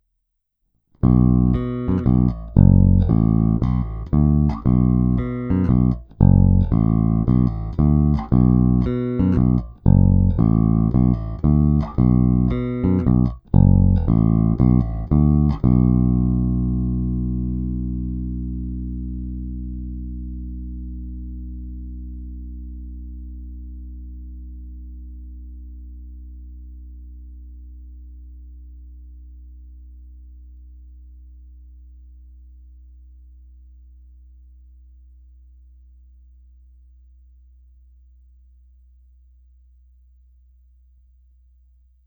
V každé poloze je zvuk hodně konkrétní, pevný, zvonivý.
Není-li uvedeno jinak, následující nahrávky jsou provedeny rovnou do zvukové karty, jen normalizovány, jinak ponechány bez úprav.
Hráno vždy mezi snímači, korekce ponechány ve střední poloze.